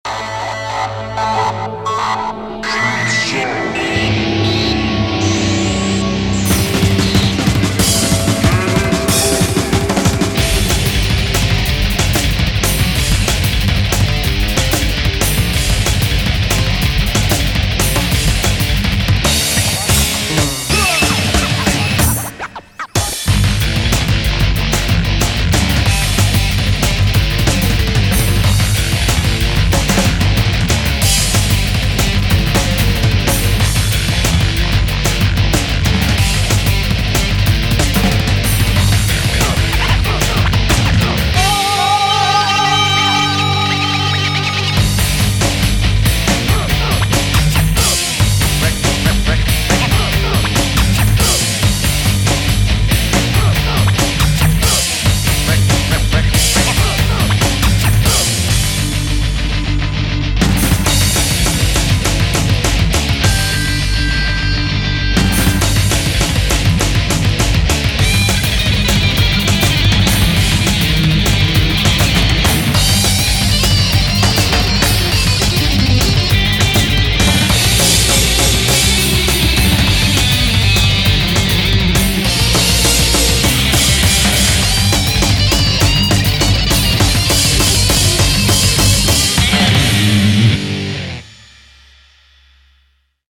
BPM140-202
Audio QualityPerfect (High Quality)
Comments[SPEED METAL]